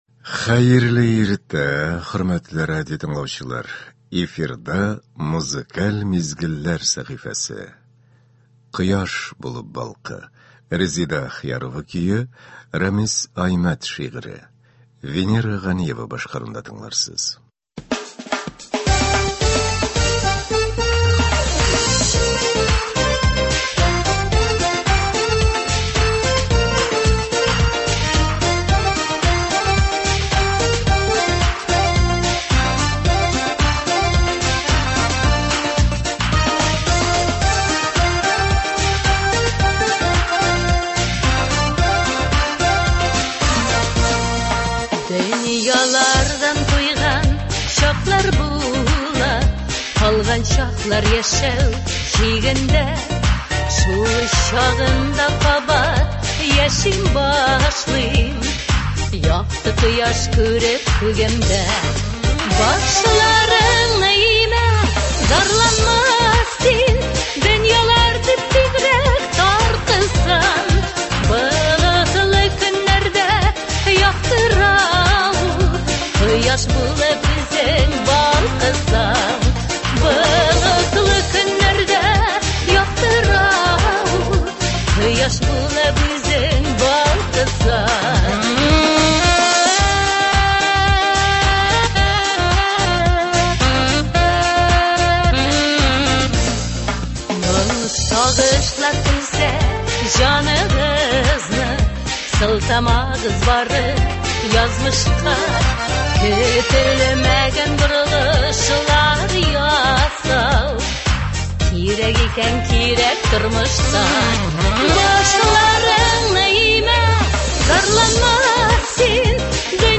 Иртәнге концерт.